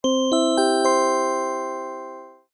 알림음 8_Correct5.mp3